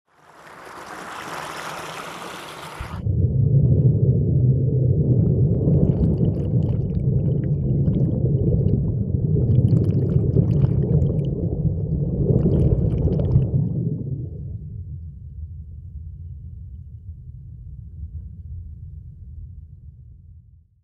Underwater submerge hydrophone